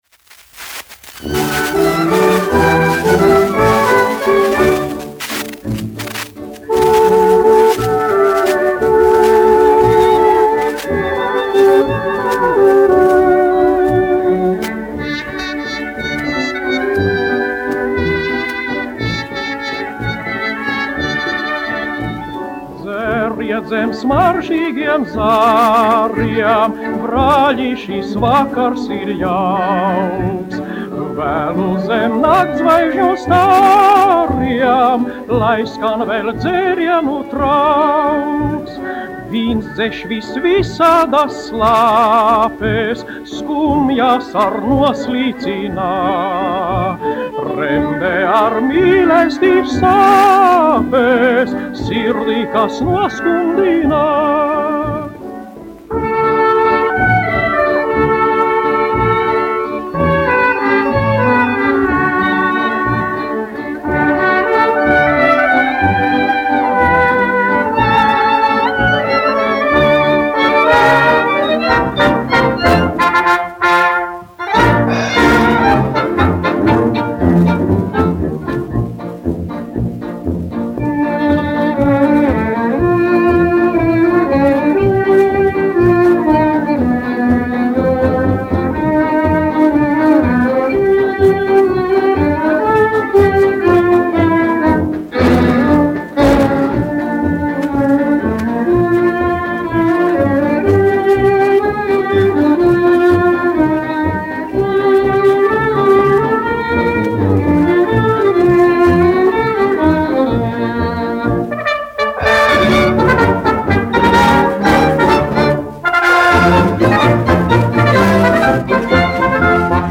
1 skpl. : analogs, 78 apgr/min, mono ; 25 cm
Marši
Skaņuplate
Latvijas vēsturiskie šellaka skaņuplašu ieraksti (Kolekcija)